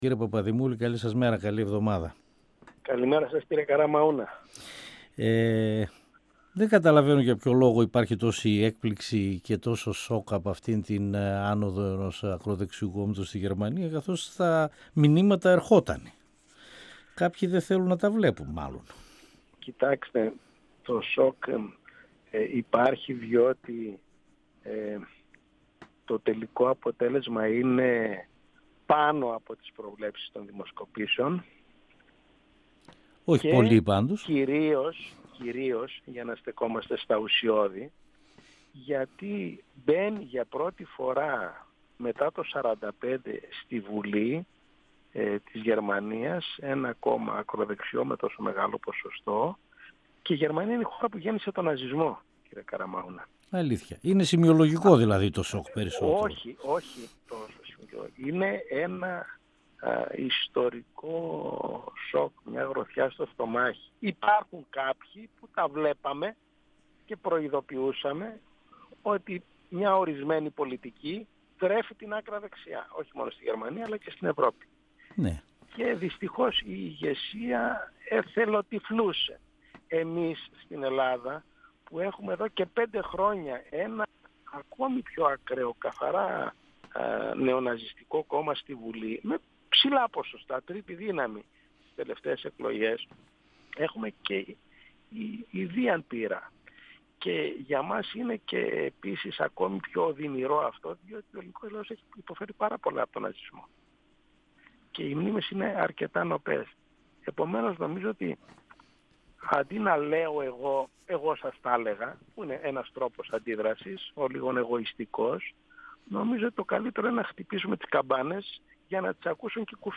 25Σεπ2017 – O Δημήτρης Παπαδημούλης , αντιπρόεδρος του Ευρωπαϊκού Κοινοβουλίου, Ευρωβουλευτής του ΣΥΡΙΖΑ στον 102 fm της ΕΡΤ3